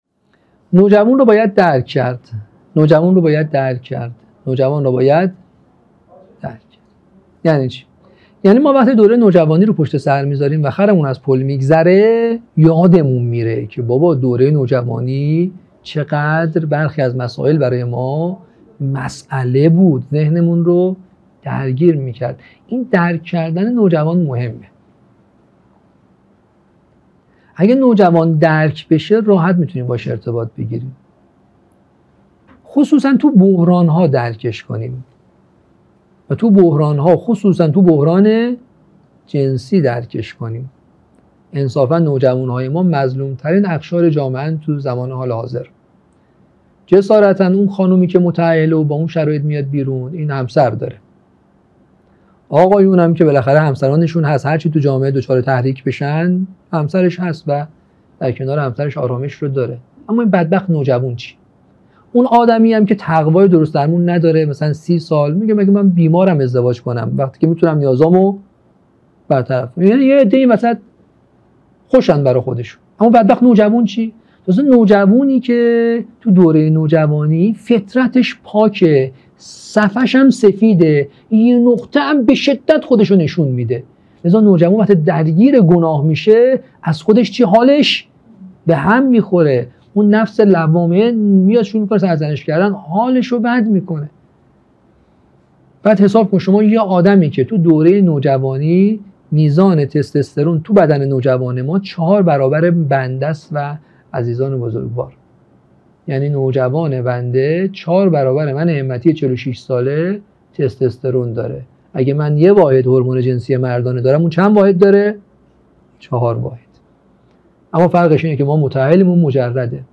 مدرسه عالی هیأت | گزیده ششم از چهارمین سلسله نشست‌ های هیأت و نوجوانان
قم - اردبیهشت ماه 1402